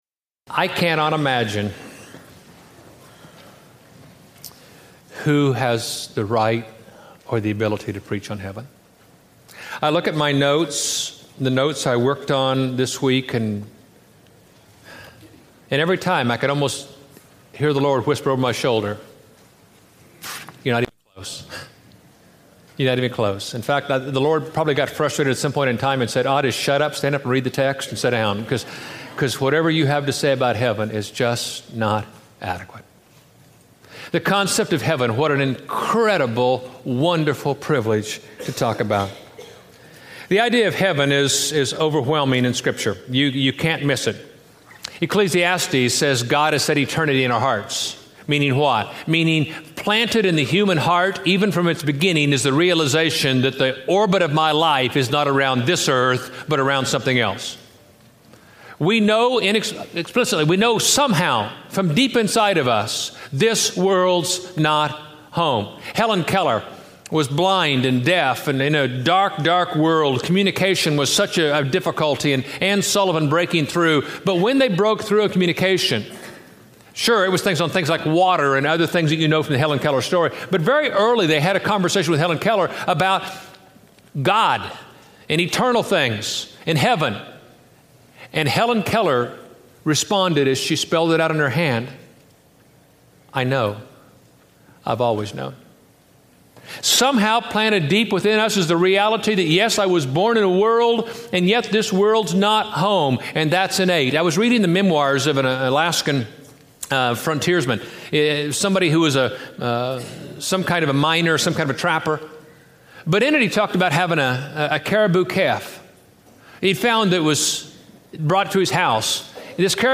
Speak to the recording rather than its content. Finally Home in One Piece Preached at College Heights Christian Church June 3, 2007 Series: Living at Peace in a World Falling to Pieces Scripture: Revelation 21-22 Audio